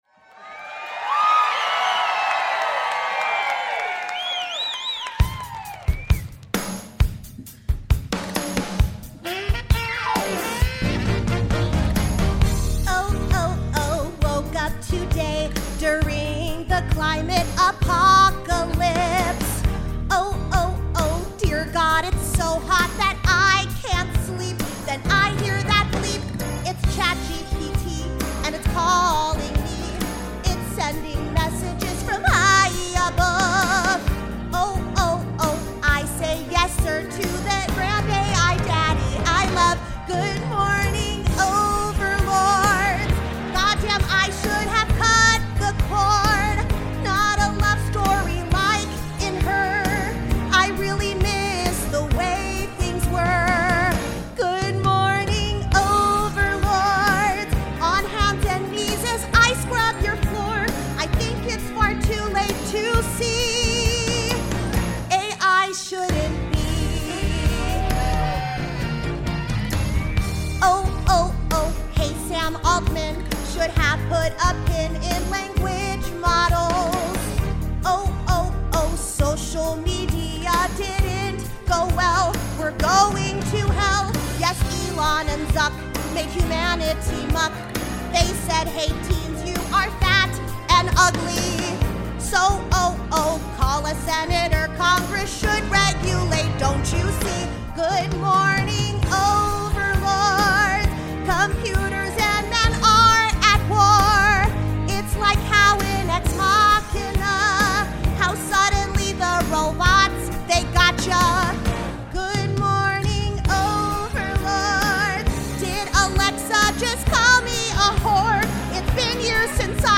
Wack Mirror (Live from San Francisco!)
Lovett Or Leave It is gay by the bay for our second tour show at San Francisco’s beautiful Palace of Fine Arts.